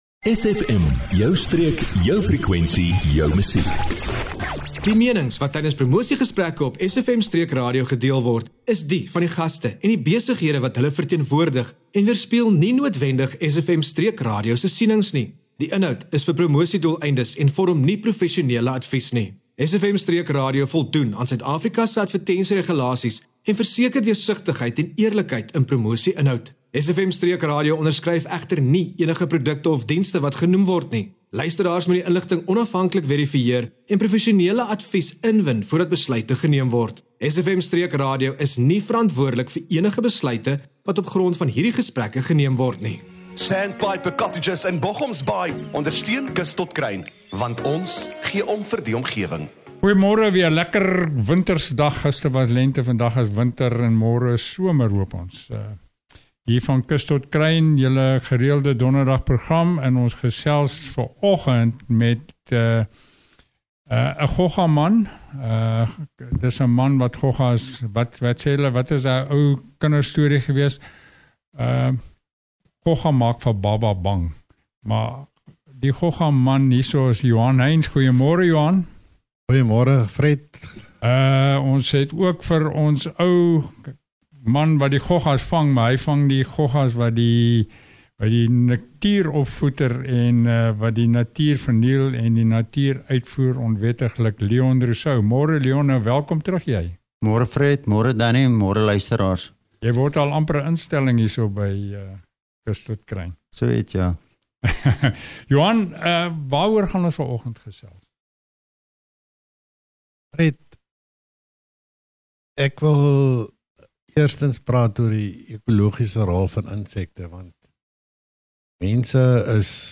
🎧 Moenie hierdie gesprek misloop nie!